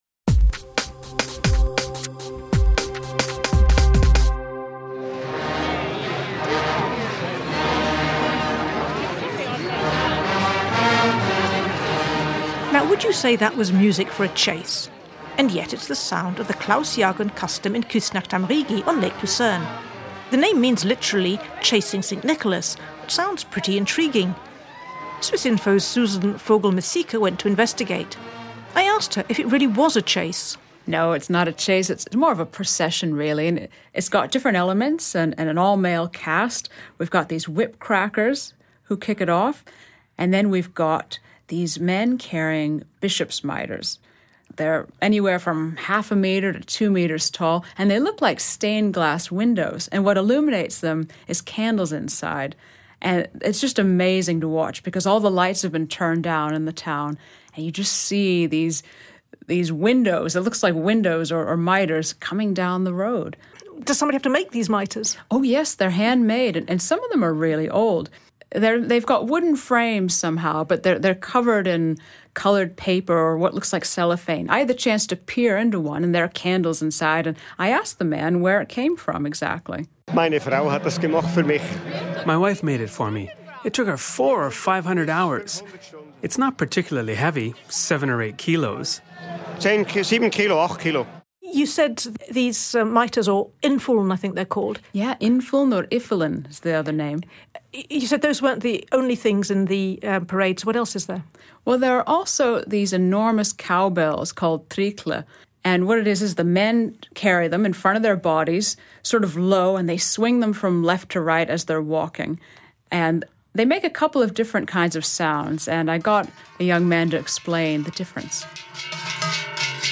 explanation of Klausjagen